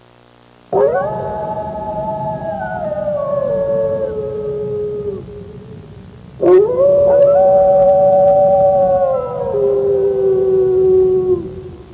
Howling Wolf Sound Effect
Download a high-quality howling wolf sound effect.
howling-wolf.mp3